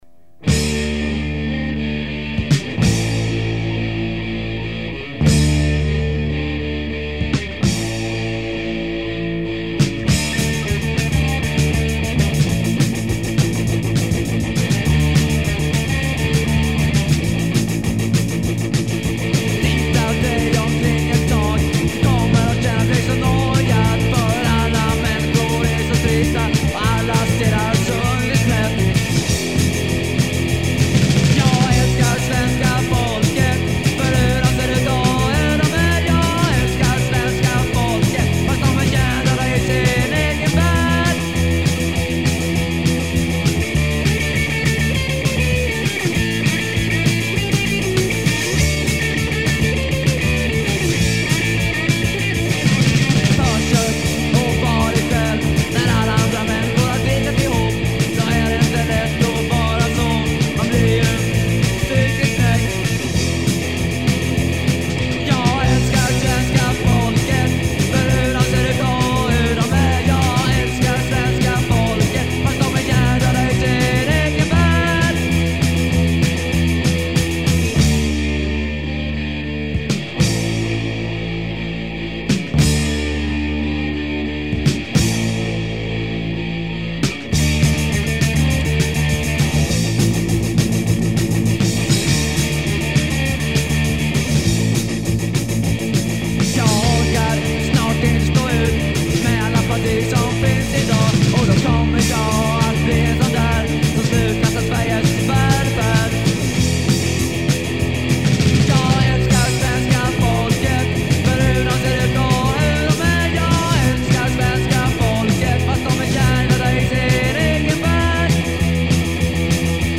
Bass
Drums, Keyboards
Guitar